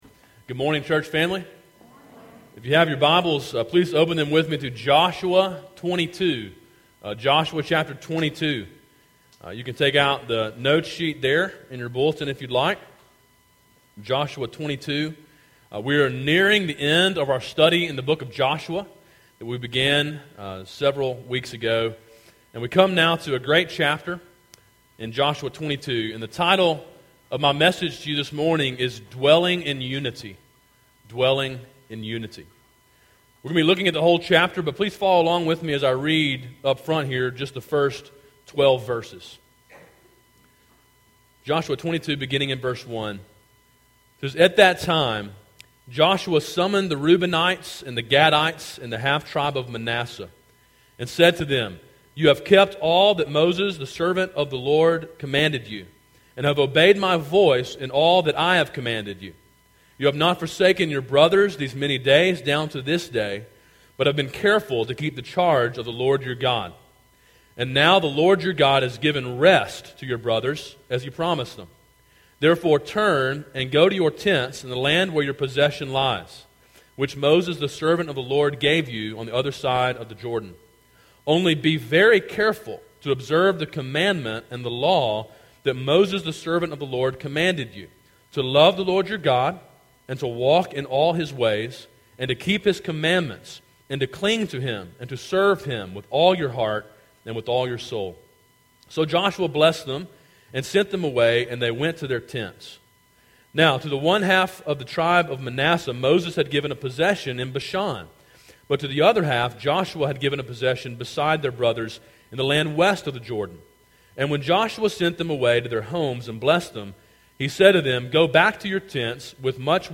A sermon in a series through the book of Joshua.